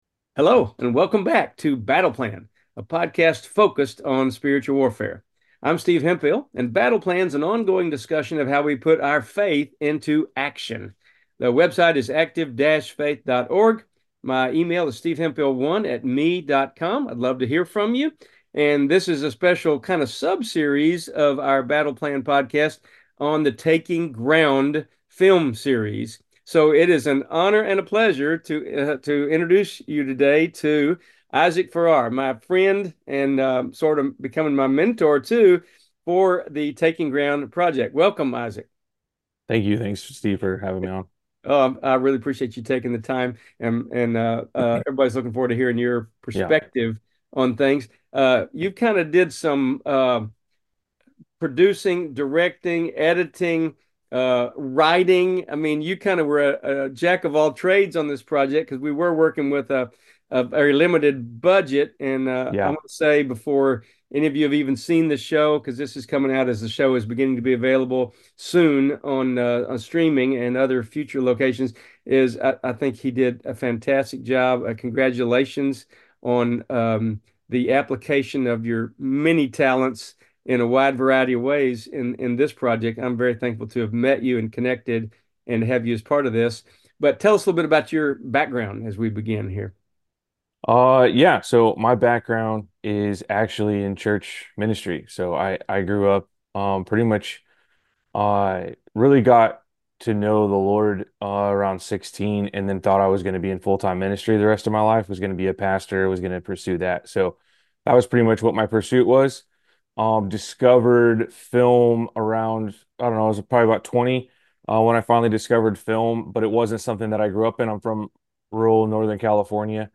Enjoy this interesting discussion about his work on our new film series, Taking Ground.